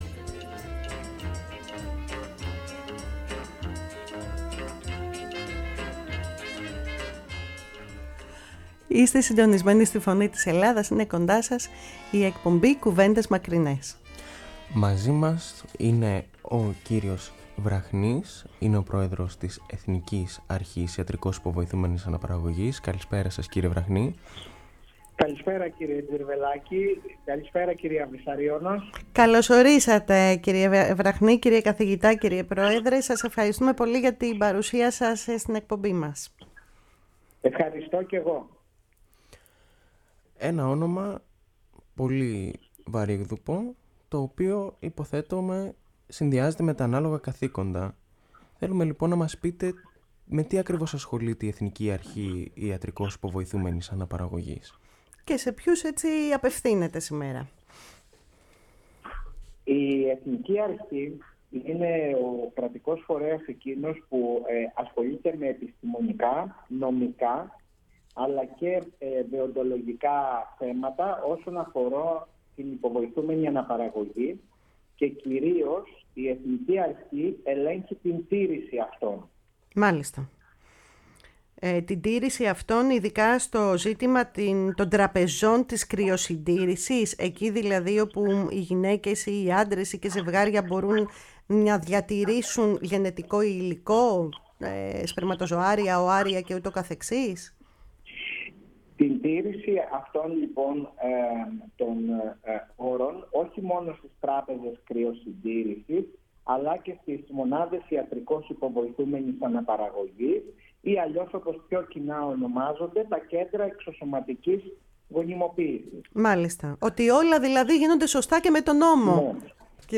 Η «Φωνή της Ελλάδας» και η εκπομπή «Κουβέντες Μακρινές» φιλοξένησαν την Τετάρτη, 26 Οκτωβρίου 2022, τον πρόεδρο της Εθνικής Αρχής Ιατρικώς Υποβοηθούμενης Αναπαραγωγής, Νικόλαο Βραχνή, με αφορμή την αλλαγή του νομοθετικού πλαισίου αναφορικά με την μέγιστη ηλικία, στην οποία μια γυναίκα μπορεί να πραγματοποιήσει μια τέτοια διαδικασία.